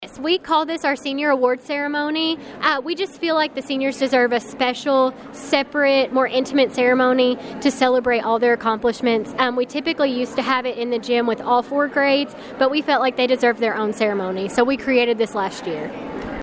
The senior class of Caldwell County High School received their graduation cords and stoles Monday morning in a special presentation in the high school Fine Arts Building.